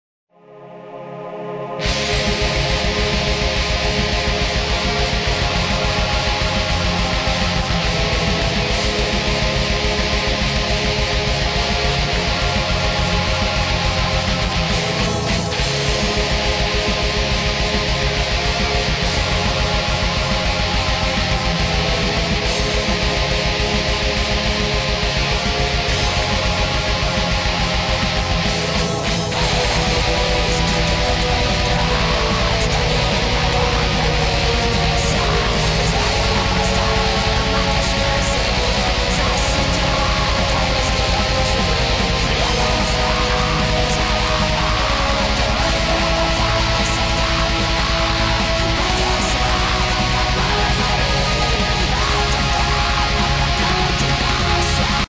Rock (320)